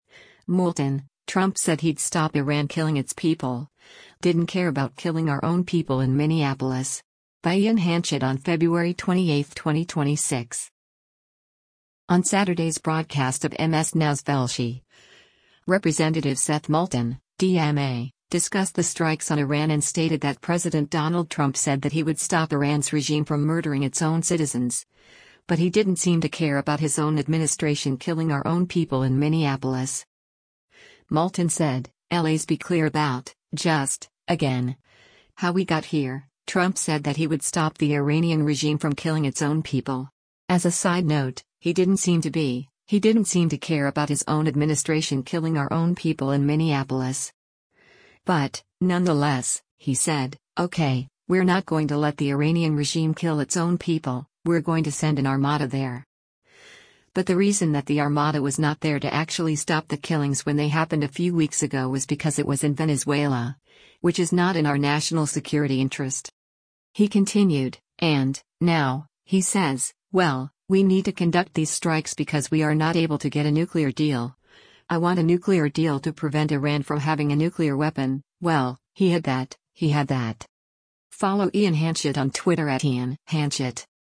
On Saturday’s broadcast of MS NOW’s “Velshi,” Rep. Seth Moulton (D-MA) discussed the strikes on Iran and stated that President Donald Trump said that he would stop Iran’s regime from murdering its own citizens, but “he didn’t seem to care about his own administration killing our own people in Minneapolis.”